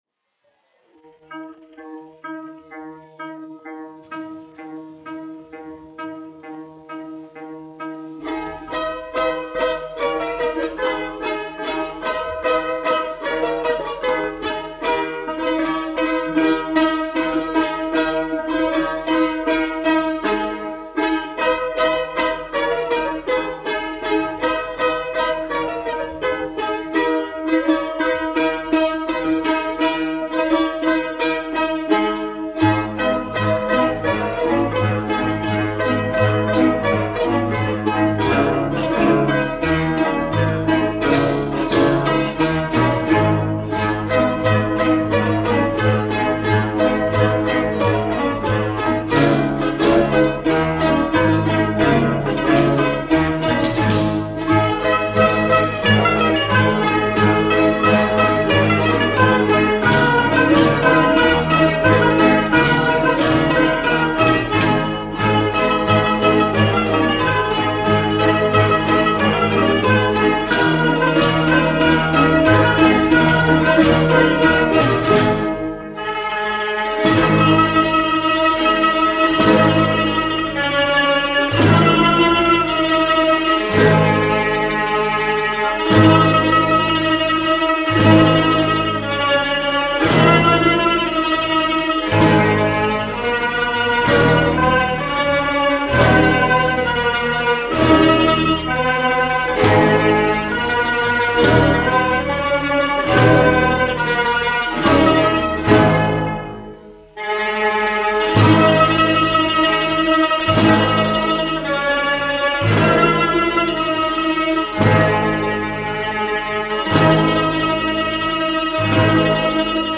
Ai utuška lugovaja : krievu tautas dziesma
1 skpl. : analogs, 78 apgr/min, mono ; 25 cm
Rīgas pilsētas krievu ģimnāzijas balalaiku orķestris
Skaņuplate